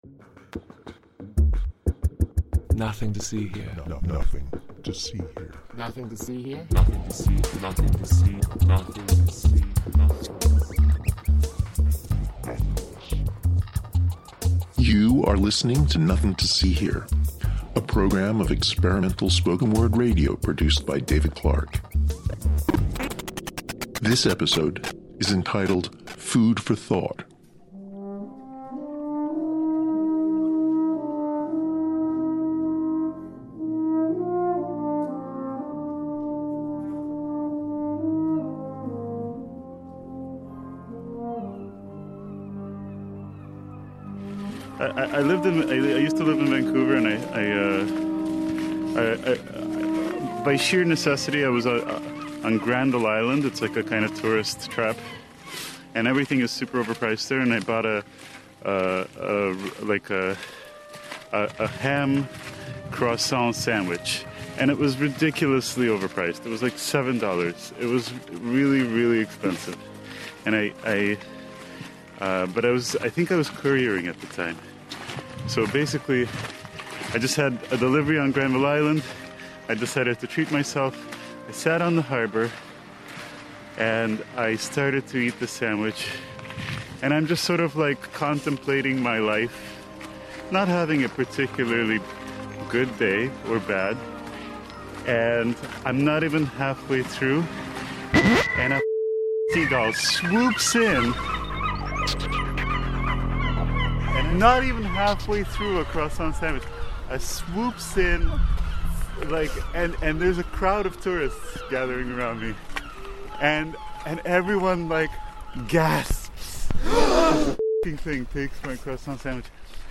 This episode was recorded in Ålvik , Norway, Amsterdam, and Halifax at NSCAD University.